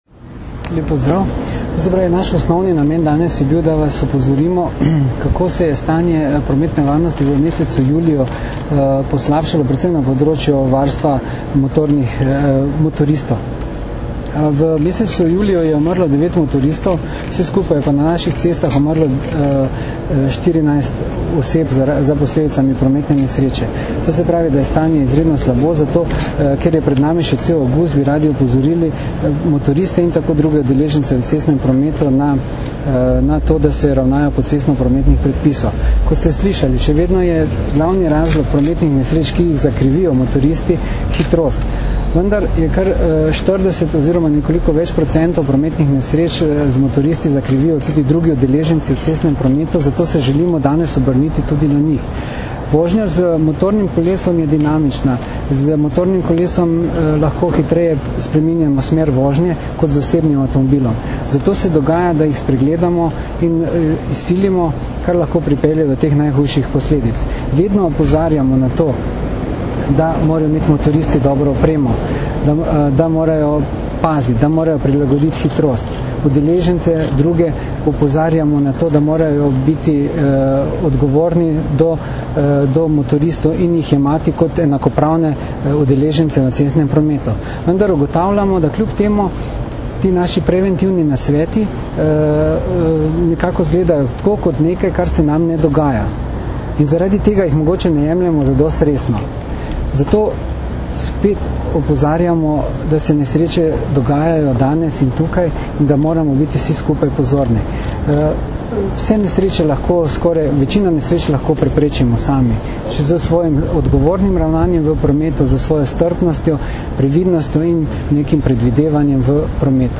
Zvočni posnetek izjave mag.